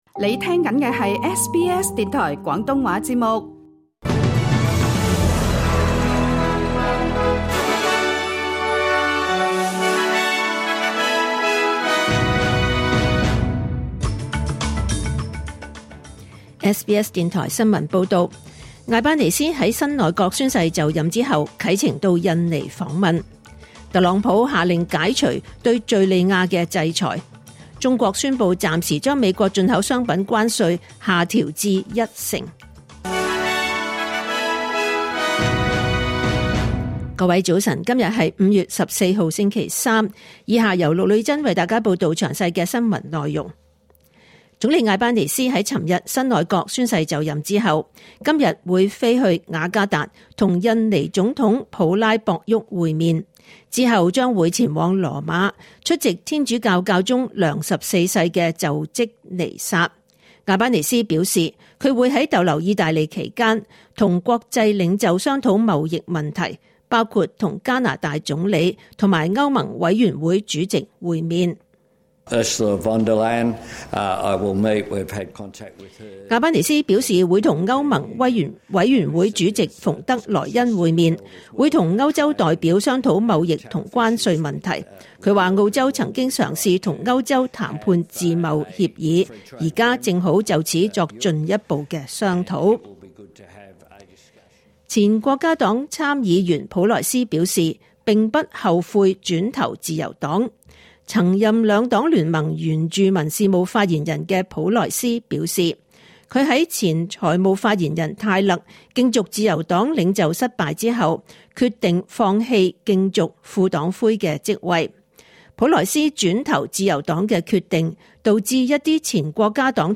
2025年5月14日 SBS 廣東話節目九點半新聞報道。